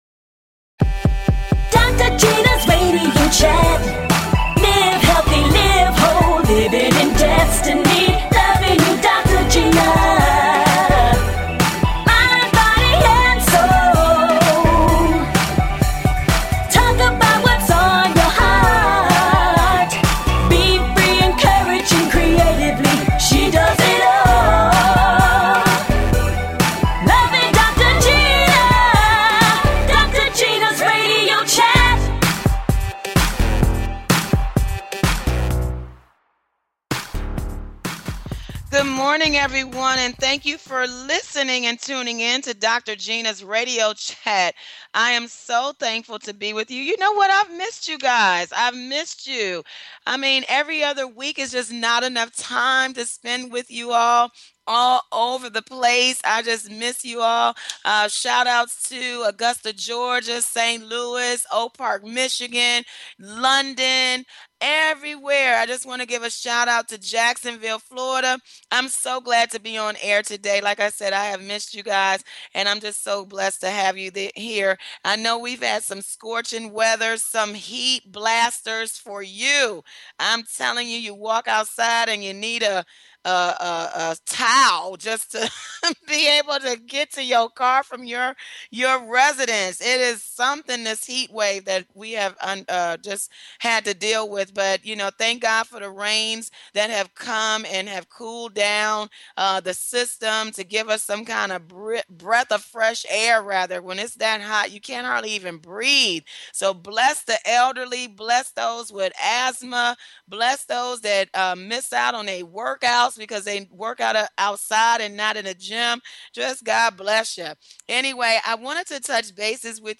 Talk Show
Exciting! And full of laughter!